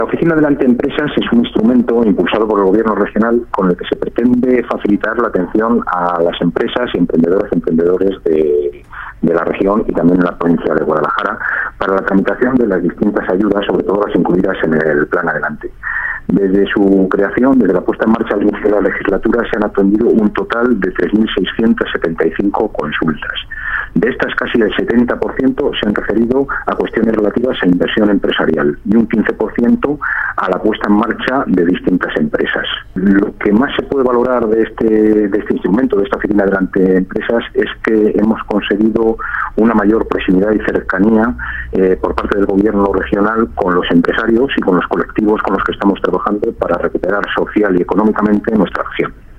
El director provincial de Economia, Empresas y Empleo en Guadalajara, Santiago Baeza, habla del funcionamiento de la Oficina Adelante Empresas en esta provincia.